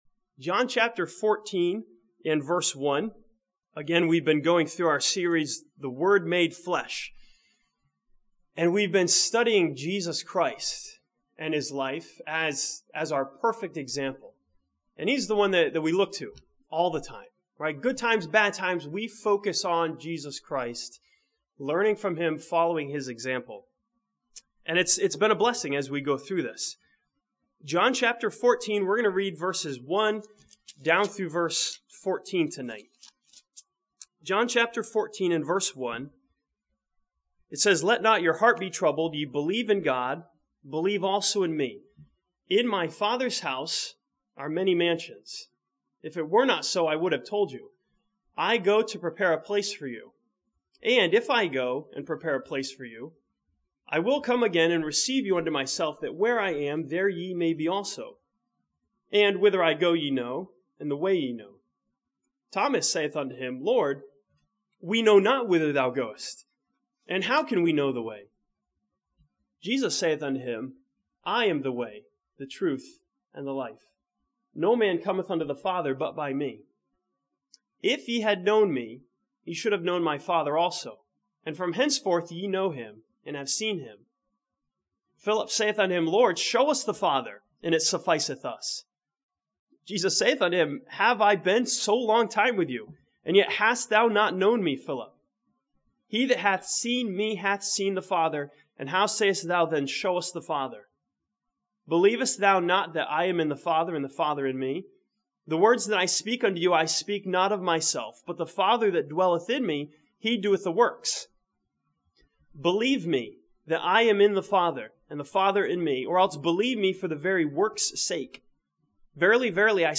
This sermon from John chapter 14 challenges believers to stick with what they have been taught and to trust the Lord when life changes.